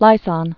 (līsän)